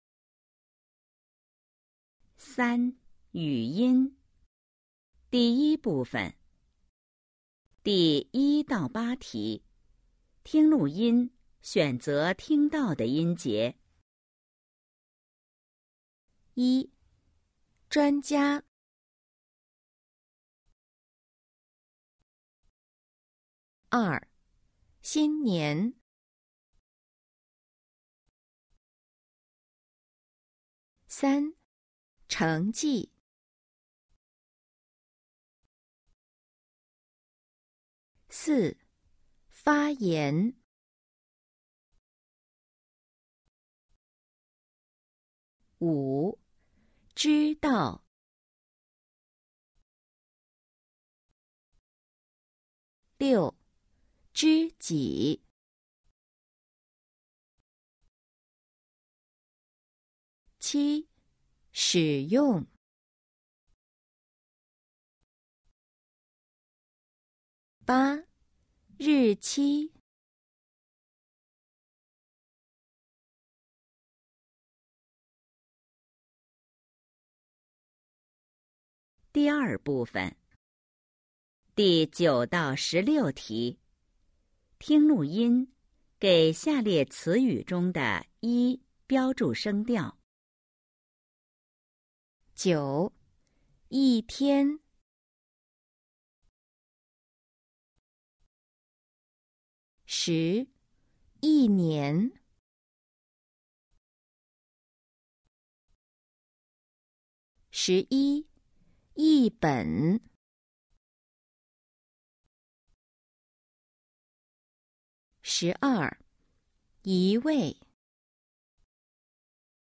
三、语音 Phần ngữ âm 🎧 04-2
Câu hỏi 1-8: Nghe bài ghi âm và đánh dấu vào âm tiết nghe được.
Câu hỏi 9-16: Nghe bài ghi âm và đánh dấu thanh điệu cho — trong các từ ngữ sau.